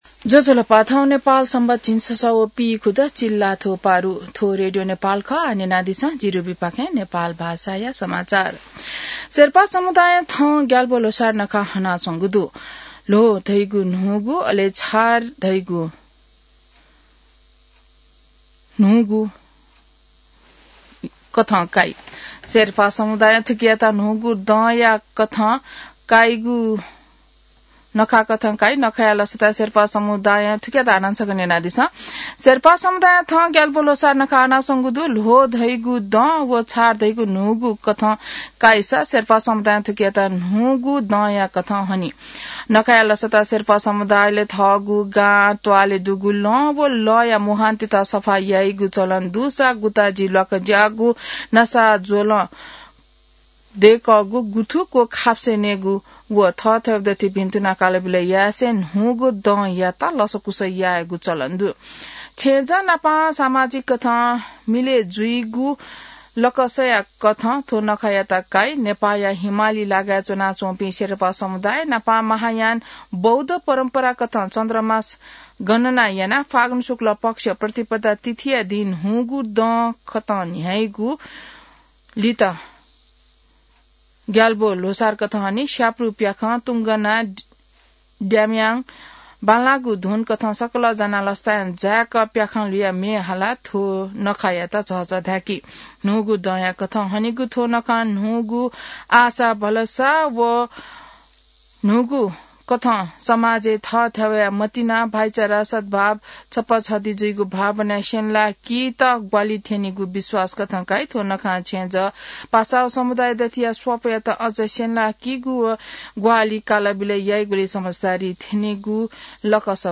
नेपाल भाषामा समाचार : ६ फागुन , २०८२